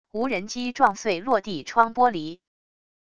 无人机撞碎落地窗玻璃wav音频